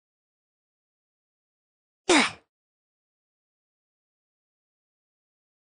new roblox death effect sound effects free download